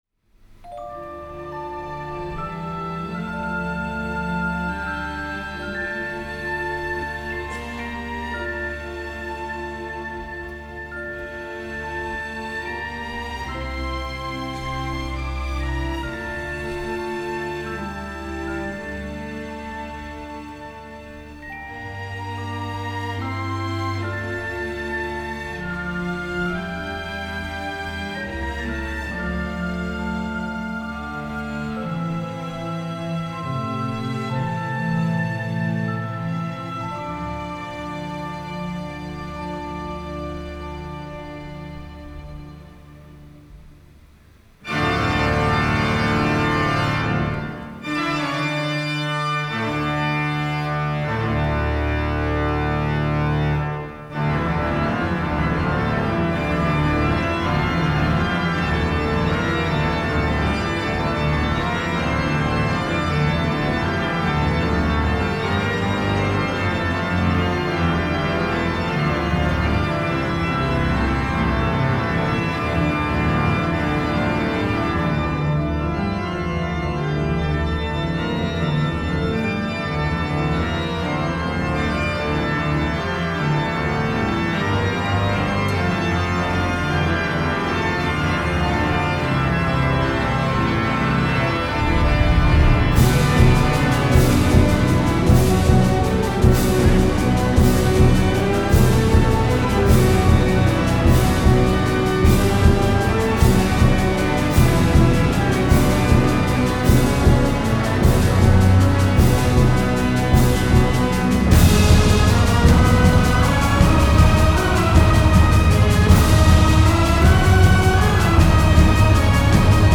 Live-Performance
organ-soloist